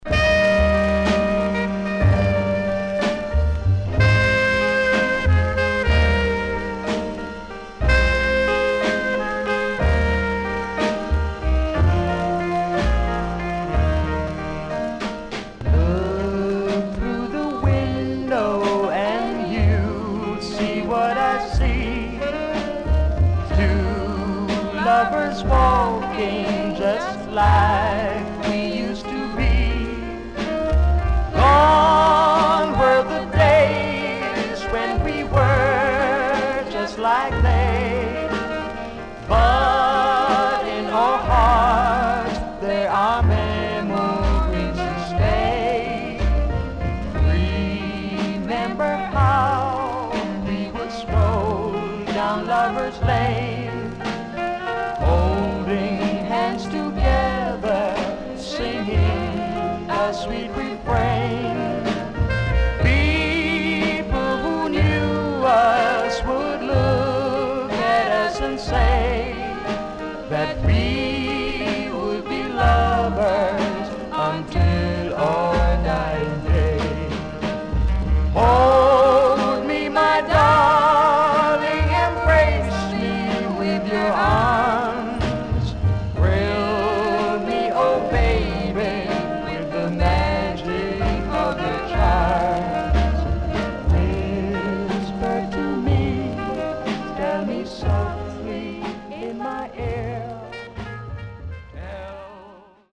HOME > SKA / ROCKSTEADY  >  SKA  >  BALLAD
SIDE A:少しチリノイズ入りますが良好です。